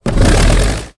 tank-engine-load-3.ogg